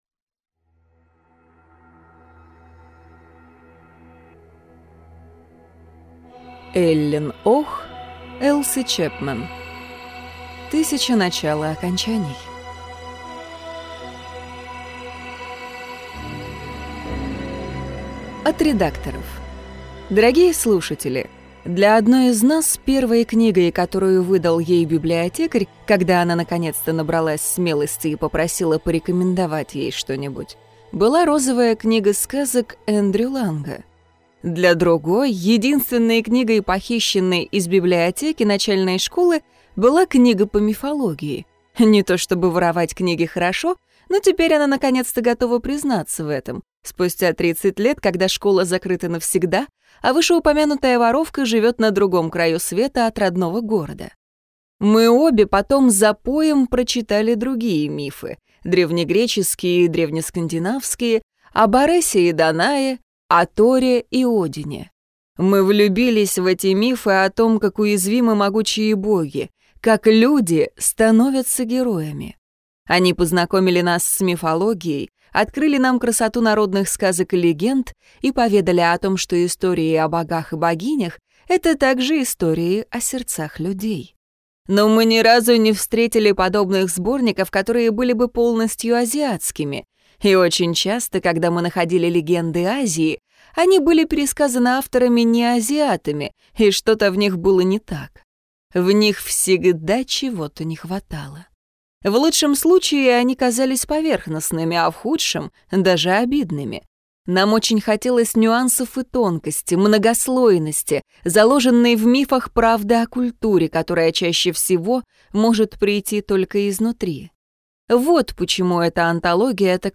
Аудиокнига Тысяча начал и окончаний | Библиотека аудиокниг
Прослушать и бесплатно скачать фрагмент аудиокниги